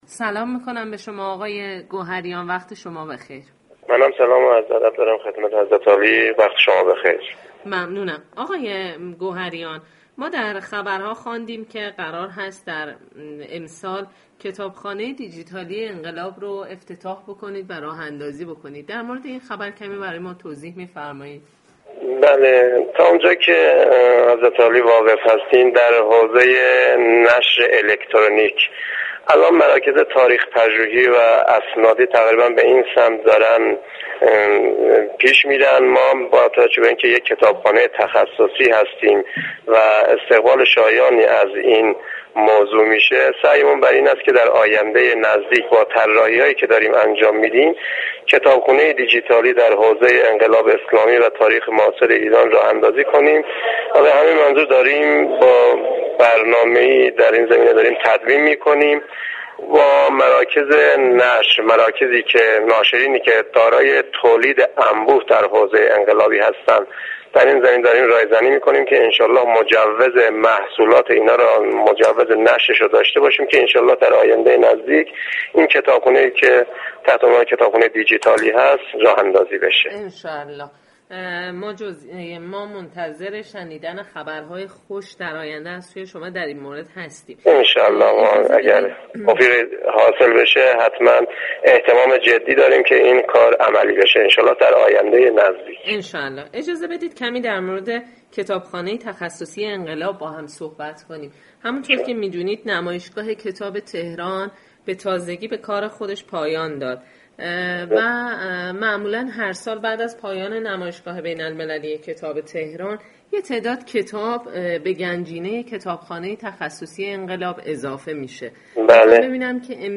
رویداد